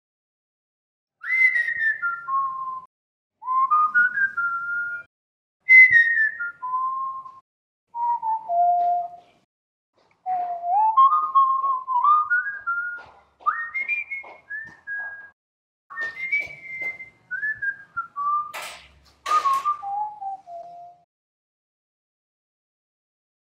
دانلود آهنگ سوت زدن 2 از افکت صوتی انسان و موجودات زنده
دانلود صدای سوت زدن 2از ساعد نیوز با لینک مستقیم و کیفیت بالا
جلوه های صوتی